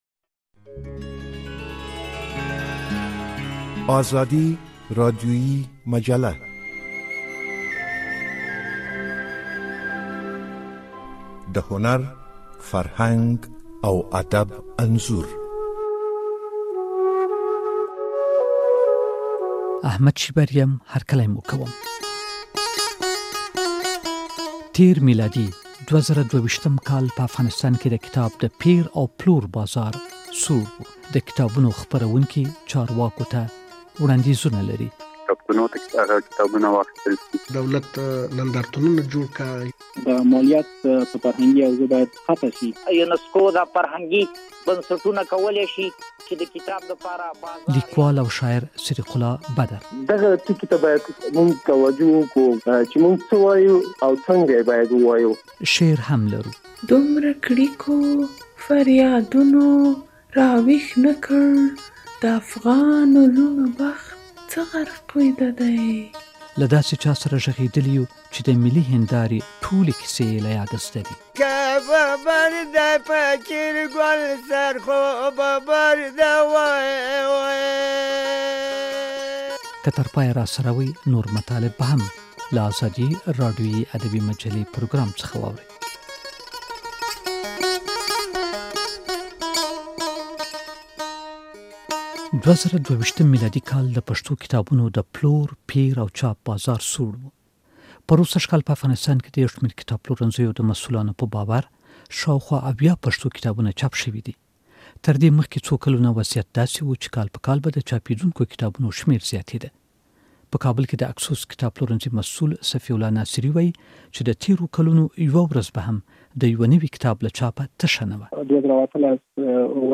په دې ادبي مجله کې هم نقد شته او هم شعر. په خپرونه کې له داسې چا سره خبرې شوي چې د ملي هندارې کیسې یې له یاده زده کړې دي.